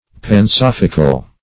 Search Result for " pansophical" : The Collaborative International Dictionary of English v.0.48: Pansophical \Pan*soph"ic*al\, a. [See Pansophy .] All-wise; claiming universal knowledge; as, pansophical pretenders.